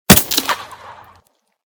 / gamedata / sounds / material / bullet / collide / tree01gr.ogg 22 KiB (Stored with Git LFS) Raw History